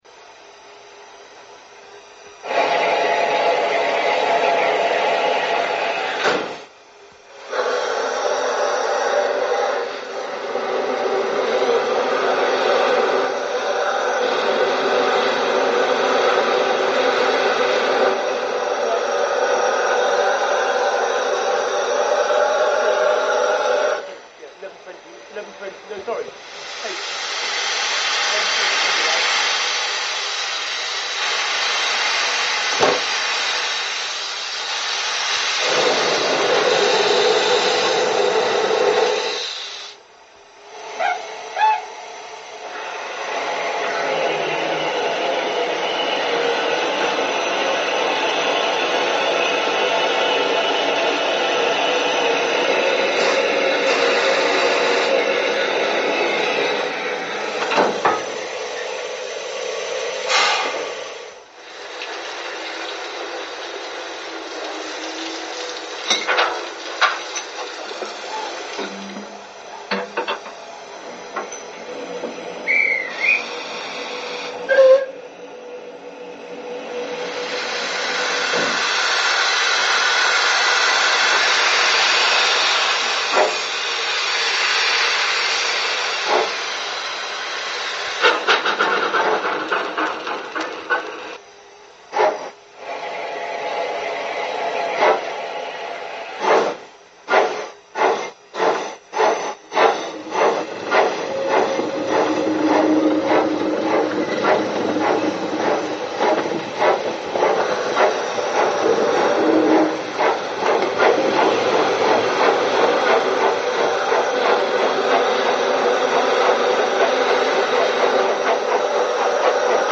Creators of authentic British digital model railway sounds for Zimo DCC decoders
Configuration: 4-6-2 tender
Cylinders: 3
Recordings: Manston (MS PREMIUM project)
Some sounds in a project may be 'generic', but the key parts of a project are always genuine sounds from the correct locomotive, unless otherwise stated.